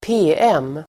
Uttal: [²p'e:em:]